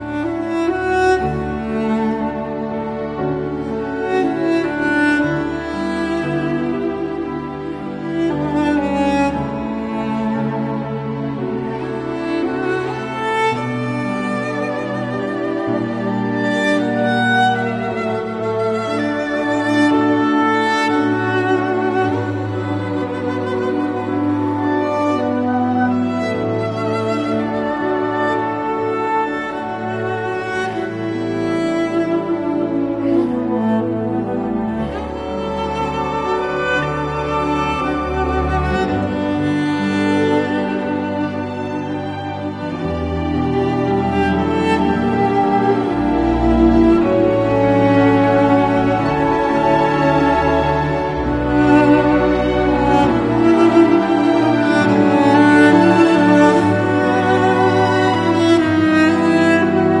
cello makes you feel emotional and stronger